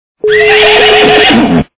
» Звуки » Природа животные » Ржание - лошади
При прослушивании Ржание - лошади качество понижено и присутствуют гудки.
Звук Ржание - лошади